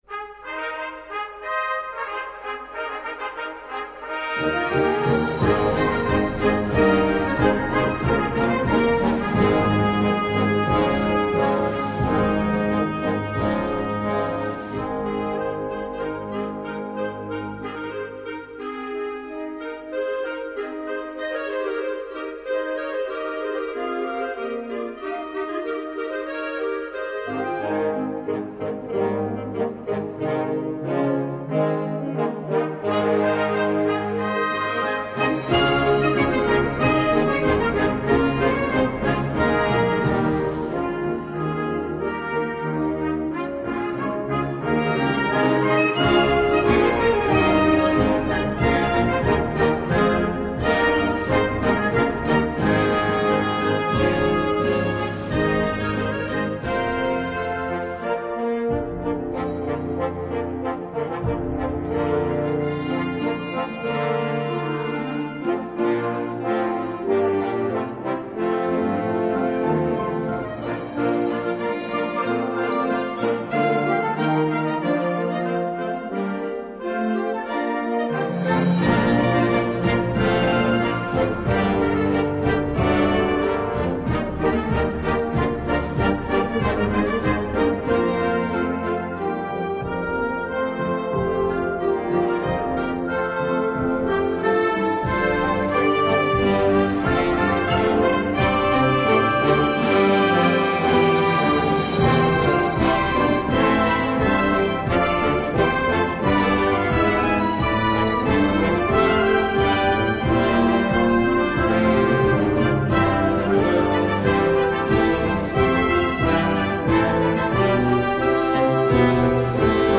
Gattung: Konzertstück
Besetzung: Blasorchester
majestätische Hymne